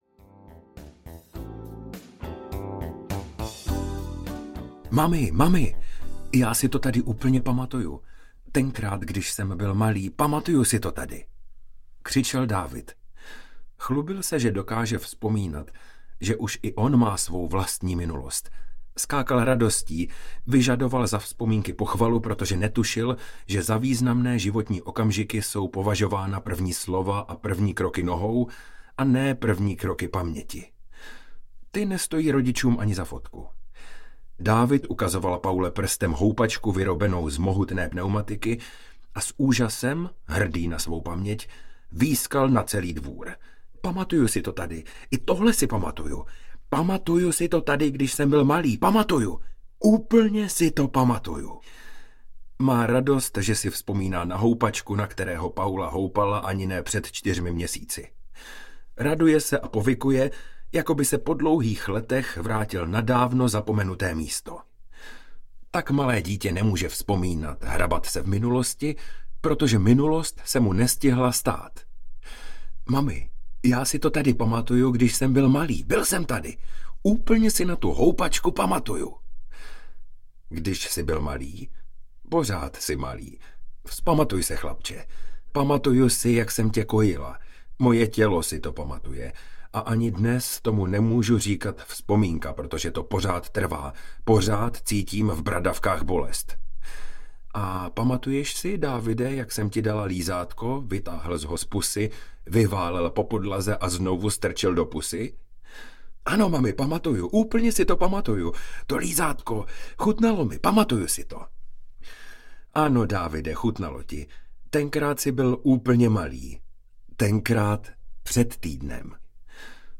Jsem Paula audiokniha
Ukázka z knihy